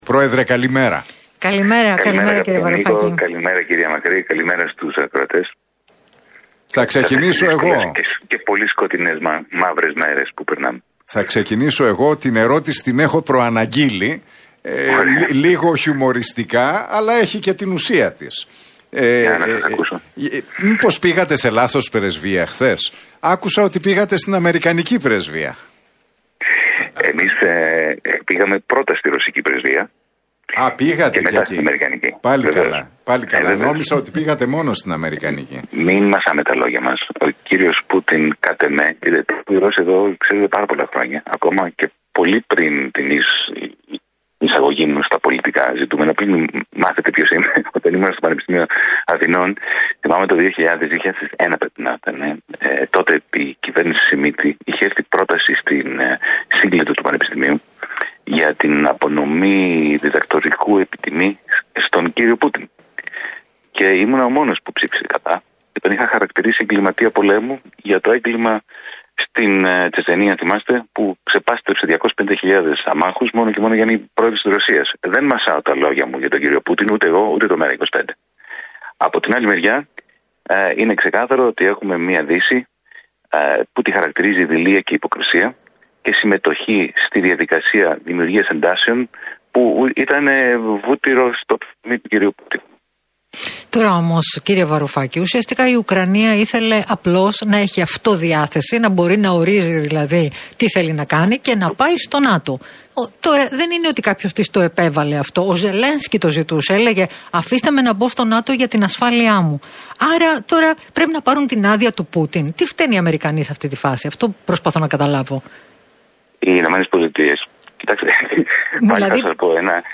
σε συνέντευξή του